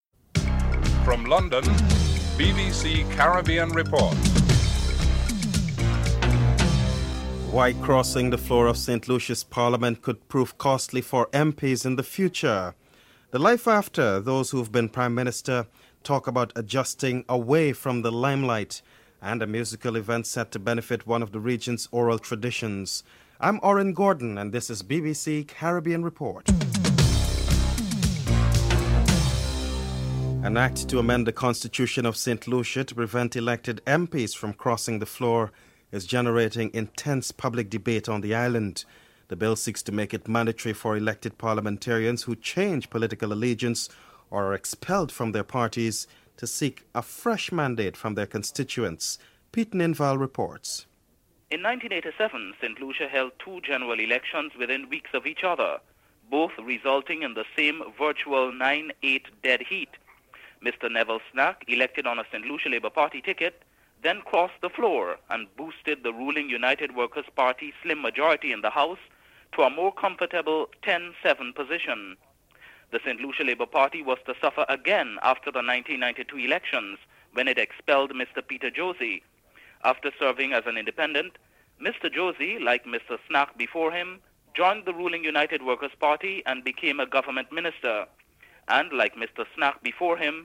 4. Former Prime Ministers of the Caribbean region speak about their role in political life and activities that engage their attention after retirement (04:51- 9:42)